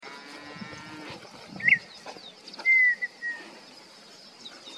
Colorada (Rhynchotus rufescens)
Nombre en inglés: Red-winged Tinamou
Fase de la vida: Adulto
Localización detallada: Reserva Natural Urbana de General Pico (laguna La Arocena)
Condición: Silvestre
Certeza: Vocalización Grabada
Colorada-canto-perdiz.mp3